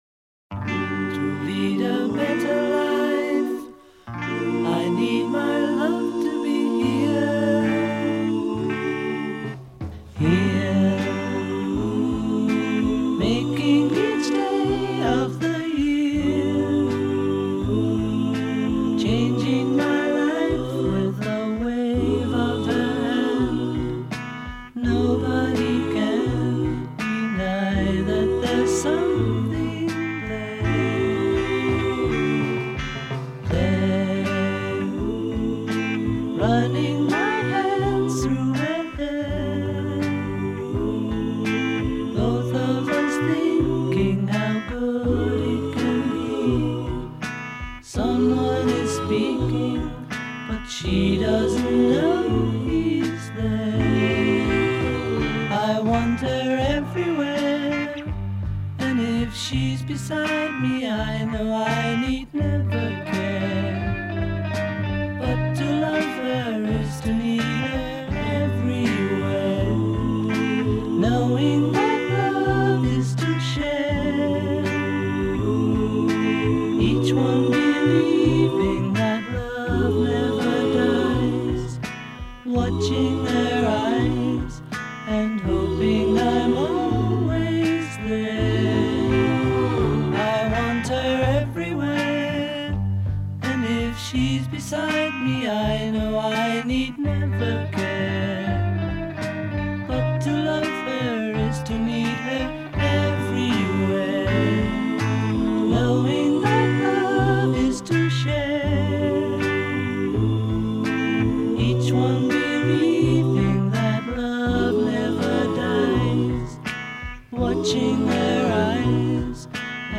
electric guitar-rock sound